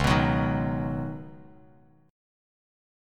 C#9sus4 chord